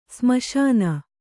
♪ smaśana